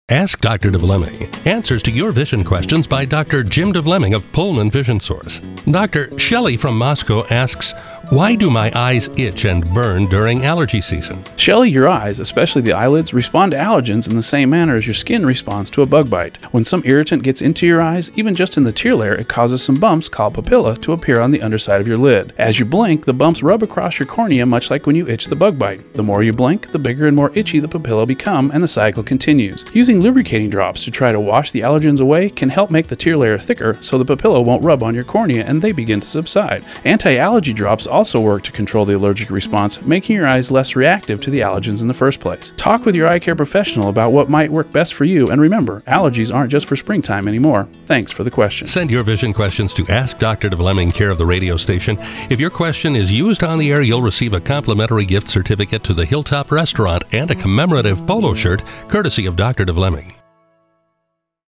Advertisers who voice their own radio commercials often find it a powerful way to leverage their knowledge and expertise, their accessibility, even their personalities to competitive advantage.
Here’s an example of one optometrist’s skillful use of radio: